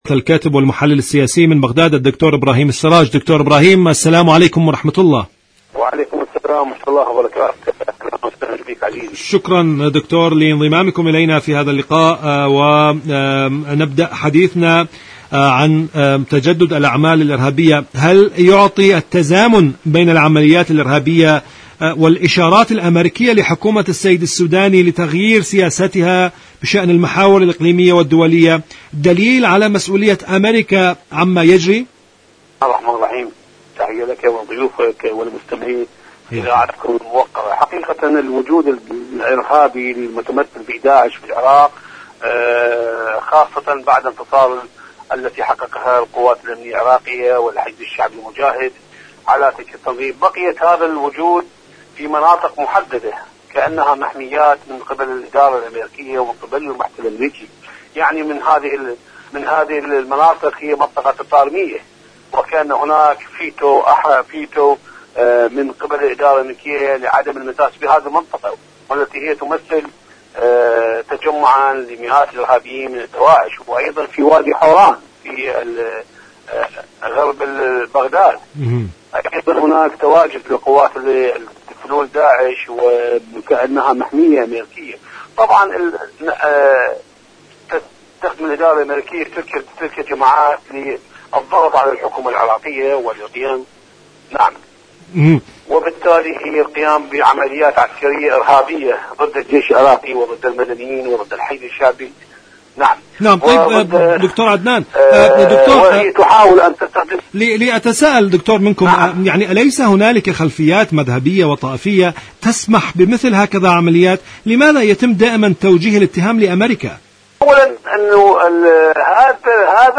مقابلات العراقيين رسالة أمريكية عراق الرافدين برامج إذاعة طهران العربية العراق برنامج عراق الرافدين الأعمال الإرهابية مقابلات إذاعية شاركوا هذا الخبر مع أصدقائكم ذات صلة إيران تعري الغطرسة الصهيوأميركية في المنطقة..